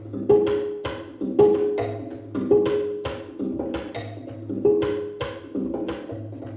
Diary Live